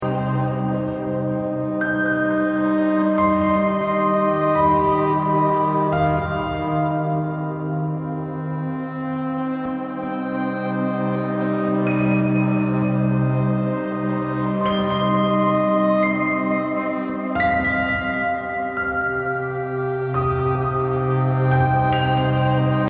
BPM 84